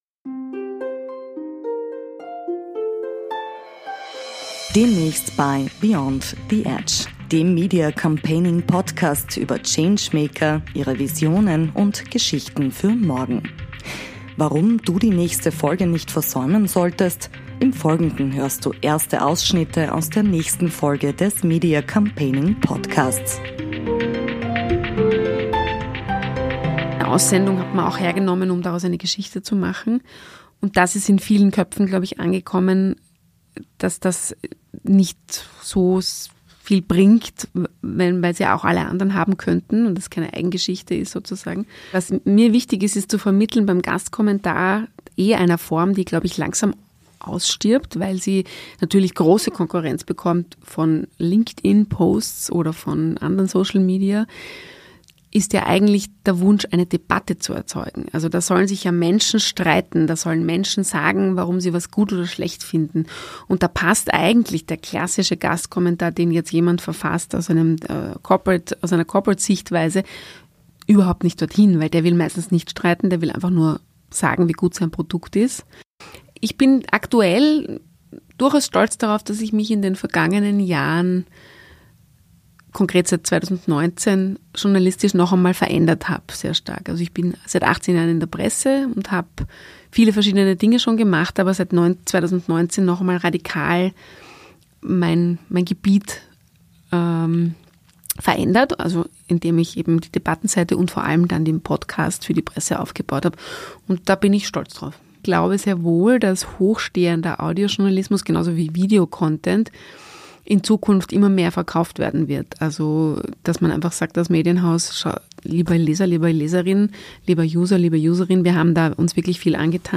Erste Ausschnitte aus dem Gespräch